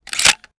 weapon_foley_drop_25.wav